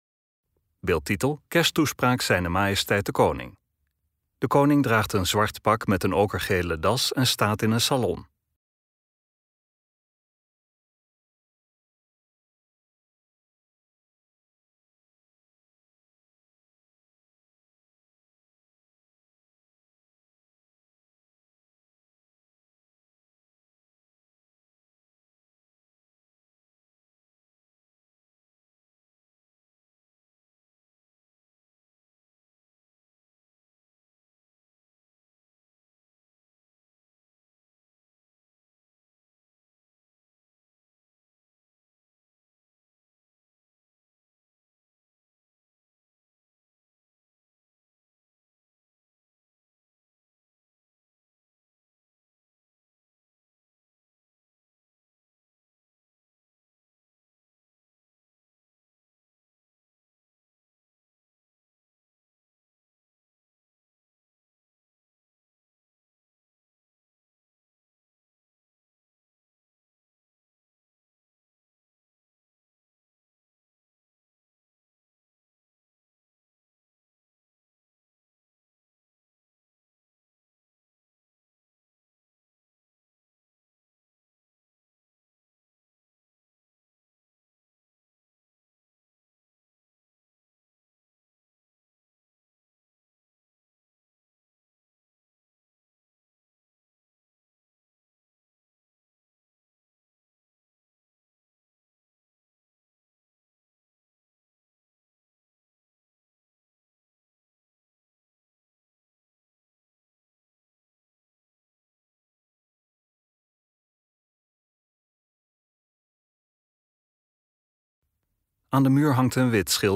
Wassenaar, 25 december 2017: Vanuit De Eikenhorst houdt Koning Willem-Alexander zijn kersttoespraak.